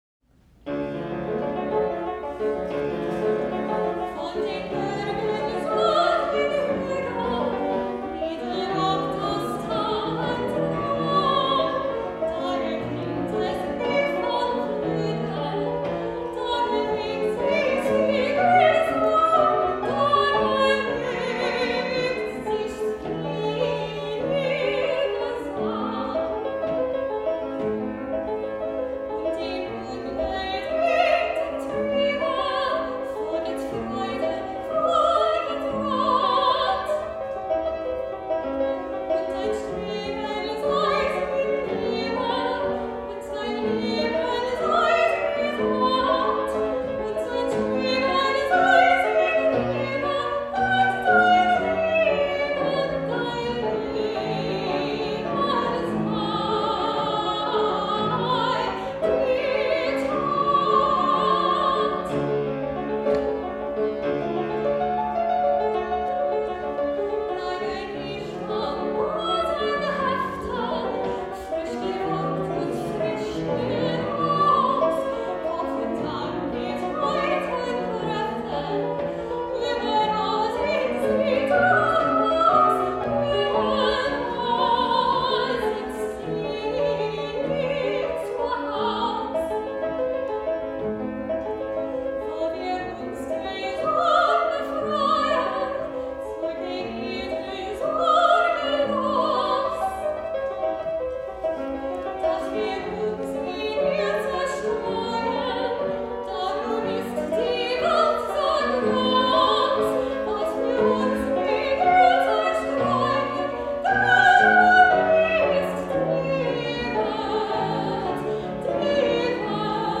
soprano
fortepiano